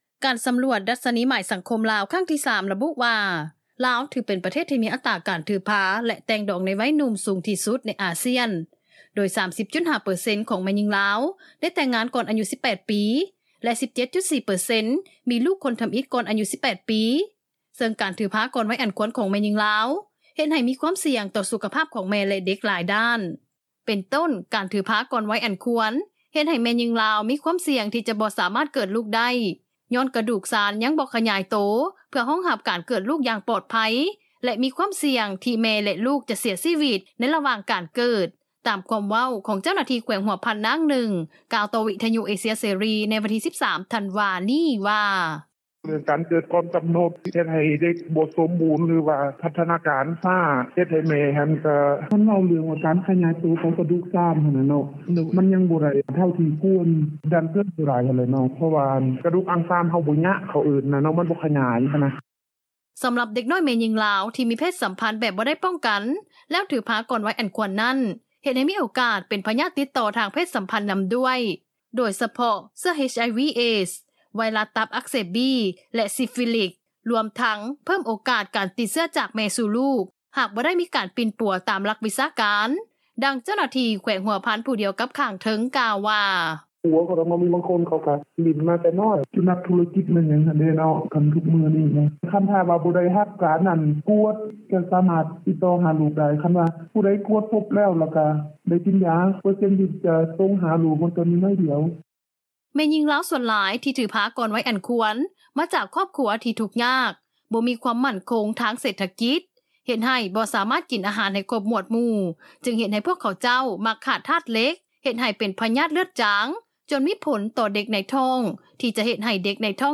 ດັ່ງເຈົ້າໜ້າທີ່ ແຂວງຊຽງຂວາງ ນາງໜຶ່ງ ກ່າວວ່າ: